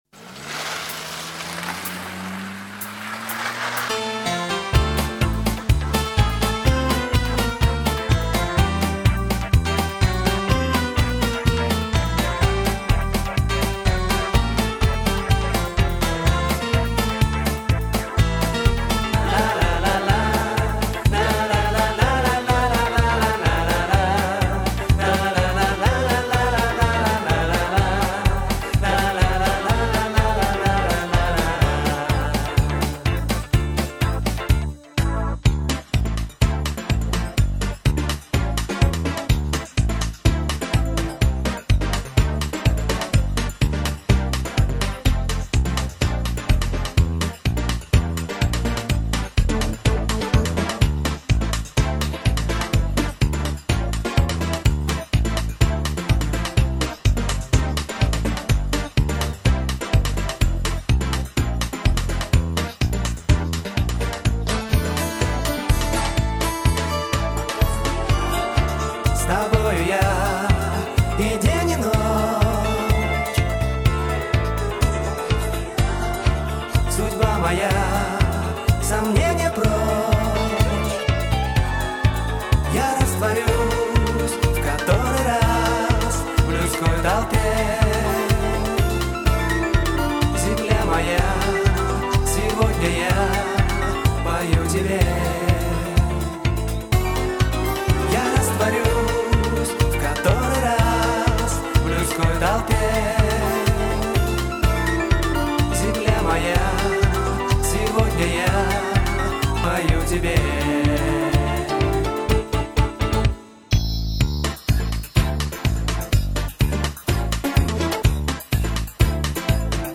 минусовка версия 99877